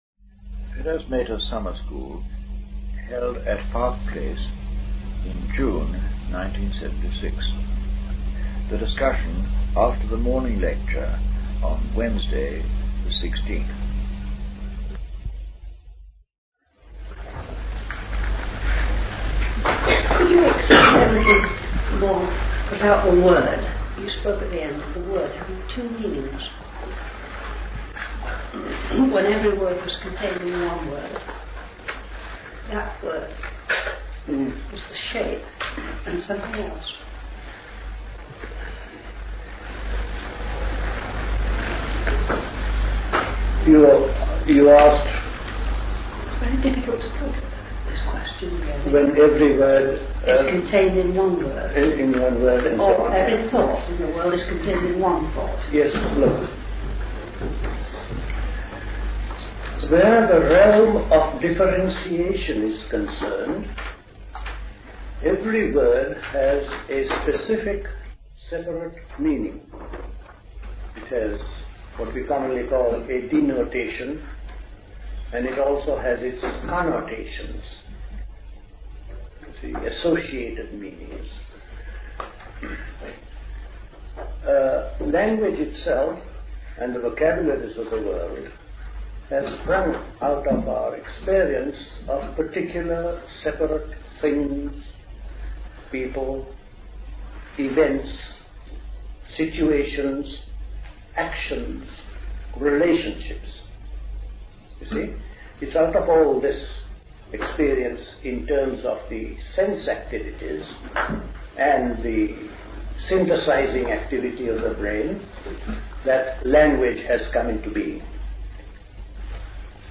Recorded at the 1976 Park Place Summer School.